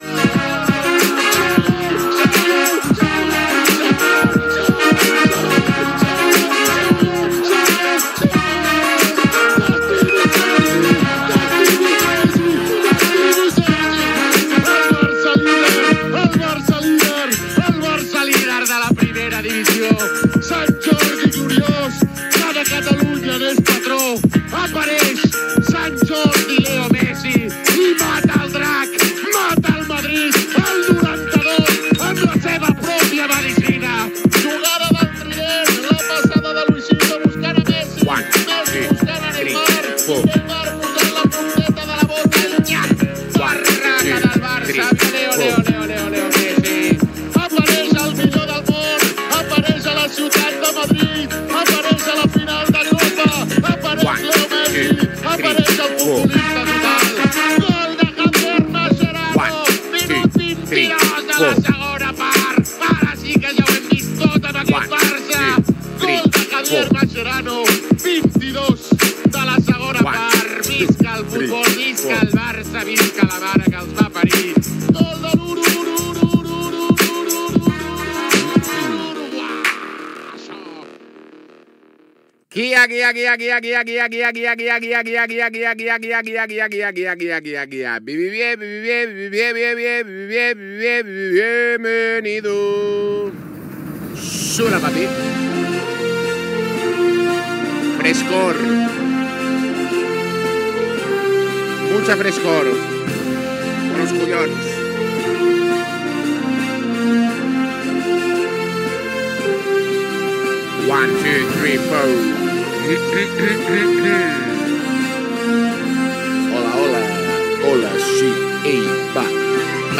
332c7bccb17615bd38701d56efc793be15f9accc.mp3 Títol Jijantes TV Emissora Jijantes TV Titularitat Tercer sector Tercer sector Altres Nom programa Jijantes TV Descripció Sintonia del canal, comentari inicial de la transmissió de l'assemblea de socis del Futbol Club Barcelona, amb agaïment a les persones que es subscriuen al canal de Twitch.
Gènere radiofònic Esportiu